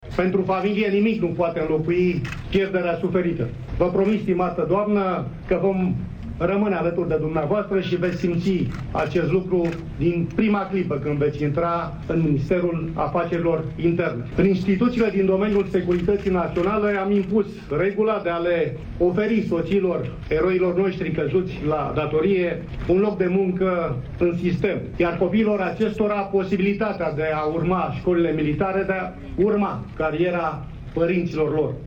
La ceremonia de la Cimitirul Ghencea -militar au fost prezenți șeful Poliției Române, Petre Tobă și vicepremierul Gabriel Oprea, care a ținut un discurs: